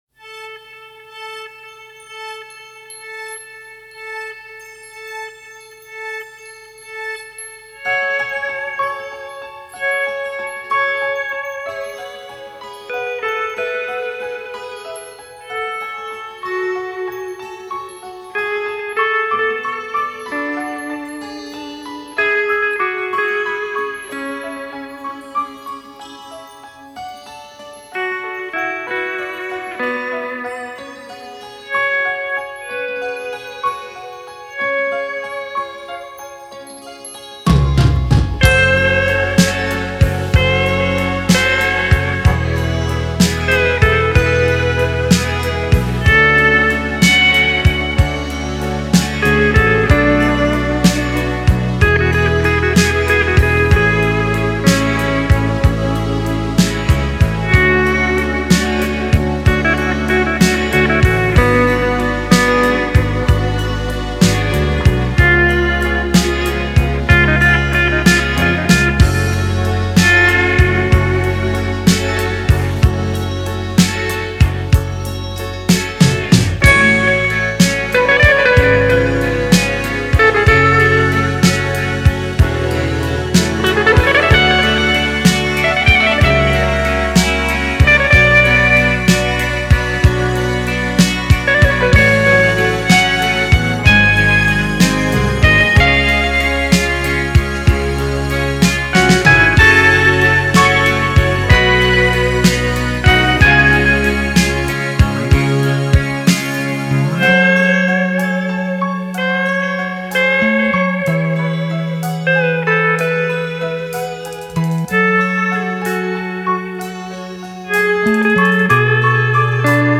Блюз-версия
романтическая
Электронного оркестра